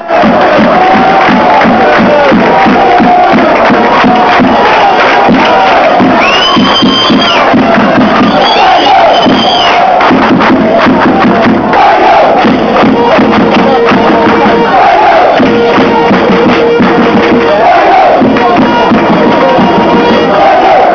Chants from the match against Orient
Quality is pretty rough but if you were there they will probably put a smile on your face, if you weren't you will probably wish you were!